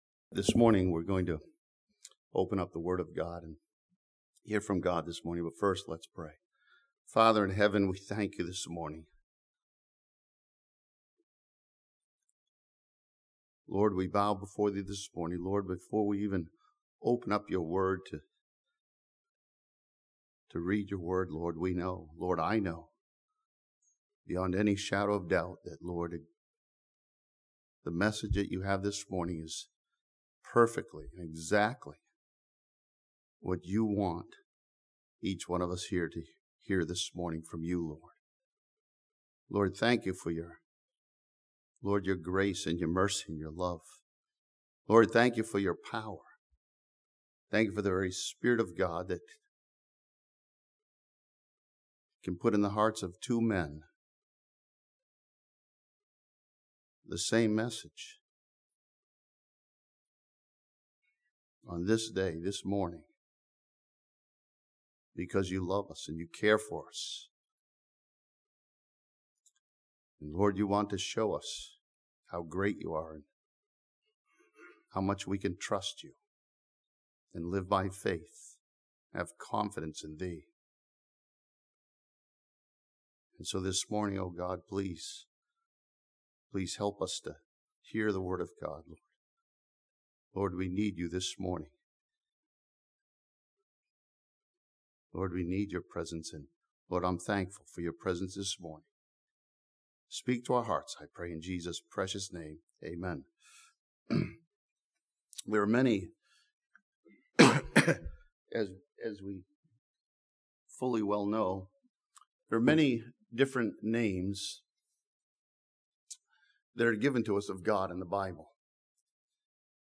This sermon from Genesis chapter 16 studies the truth that the living God sees everyone of us in our need.